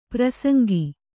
♪ prasaŋgi